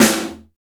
Index of /90_sSampleCDs/Roland L-CDX-01/KIT_Drum Kits 1/KIT_R&R Kit 3
SNR TRASH03R.wav